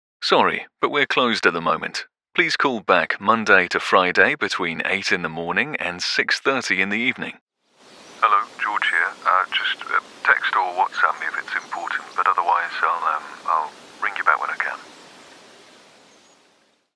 On Hold, Professional Voicemail, Phone Greetings & Interactive Voice Overs
Yng Adult (18-29) | Adult (30-50)